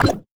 fnl/Assets/Extensions/Advanced_UI/User_Interface/Robotic/Robotic Back Button 3.wav
Robotic Back Button 3.wav